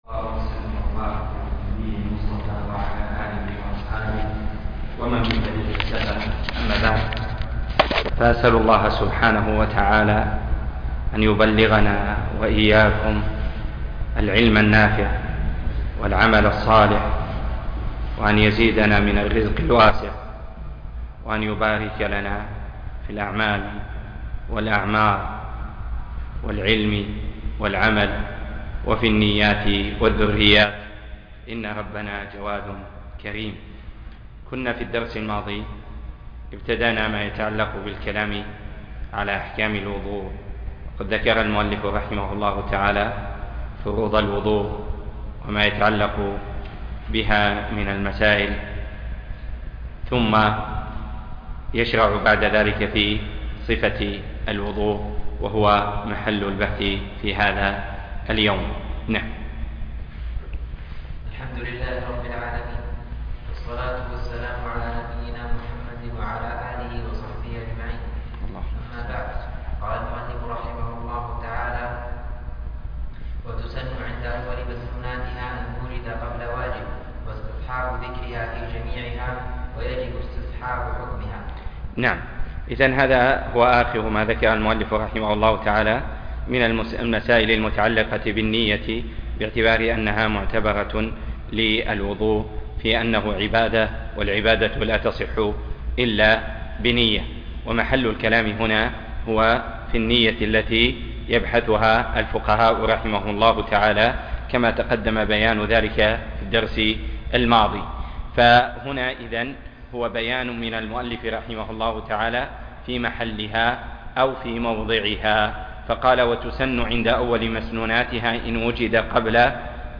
زاد المستقنع - باب فروض الوضوء - الدرس (8)